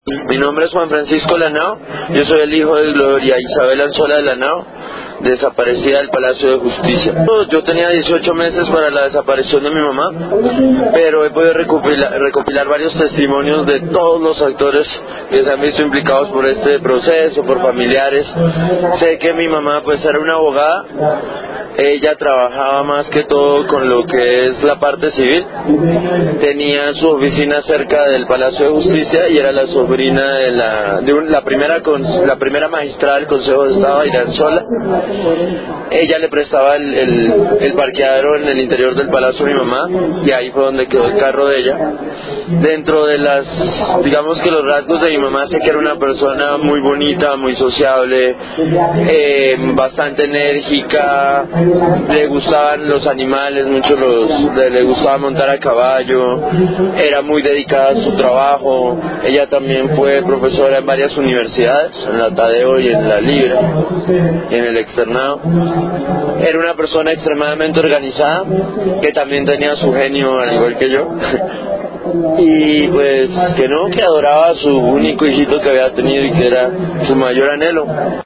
A través de las palabras y voces de los  familiares de las víctimas, recordamos a cada uno de los desaparecidos del Palacio de Justicia: